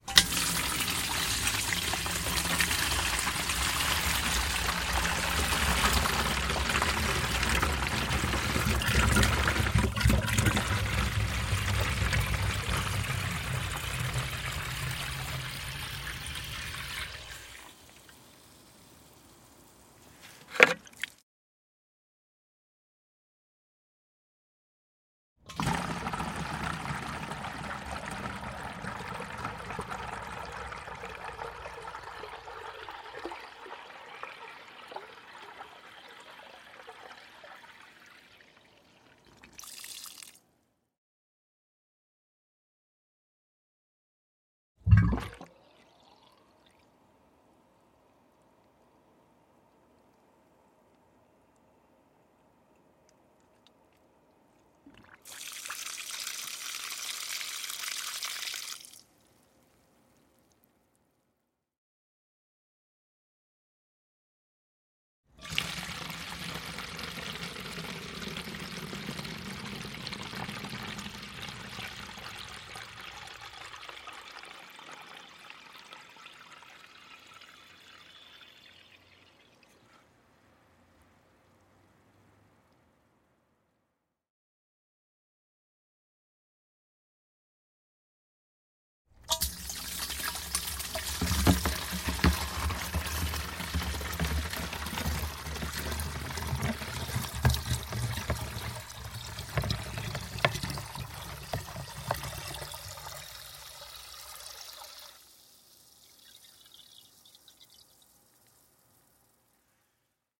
随机的 "塑料煤气罐倒在地上的煤气或水湿漉漉的马路上
描述：塑料气体容器倒在地面湿的sloppy.wav上的气体或水
标签： 接地 稀松 湿 塑料 倾倒 气体 容器
声道立体声